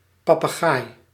Ääntäminen
IPA: /pɔ.tɑ̃s/